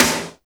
Index of /90_sSampleCDs/Roland L-CD701/SNR_Snares 2/SNR_Sn Modules 2
SNR AARGH 0G.wav